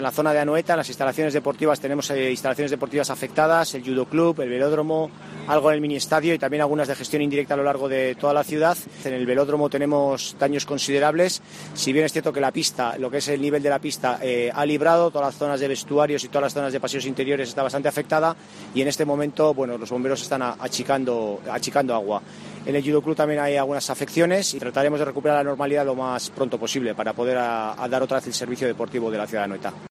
Martin Ibabe, edil de Seguridad Ciudadana de San Sebastián